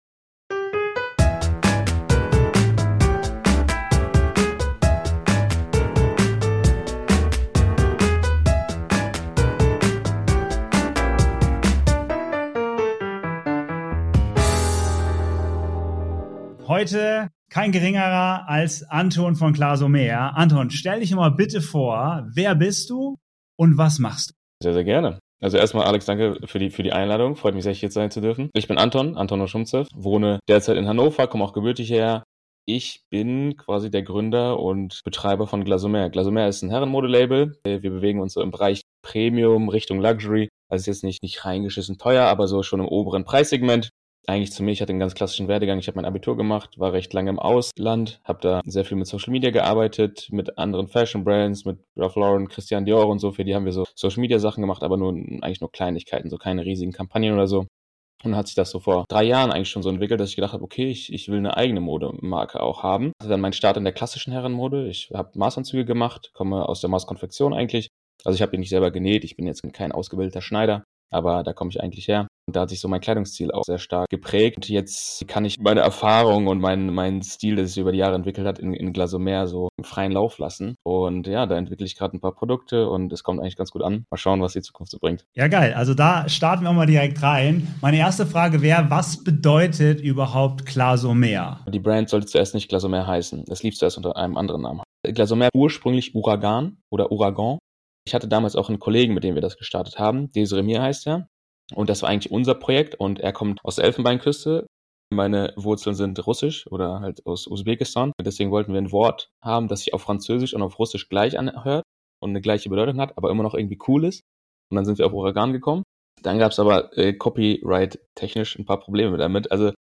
In dieser Folge interviewe ich